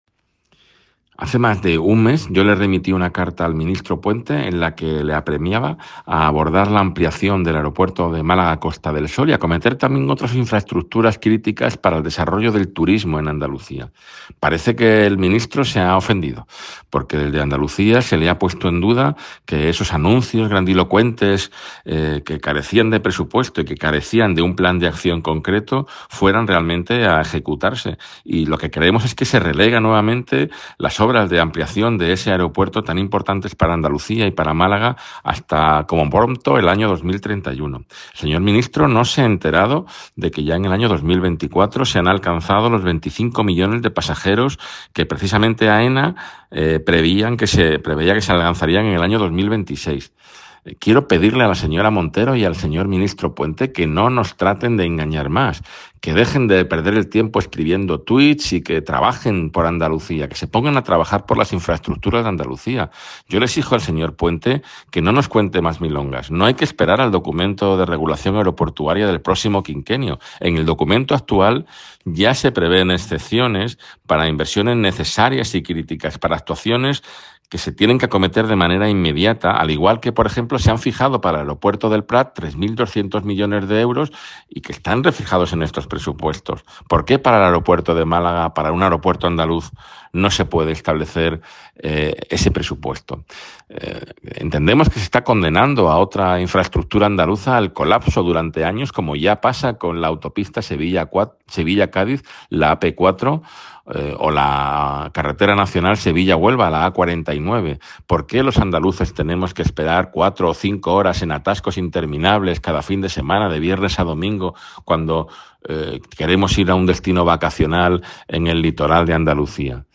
Declaraciones de Arturo Bernal, Consejero de Turismo de la Junta de Andalucía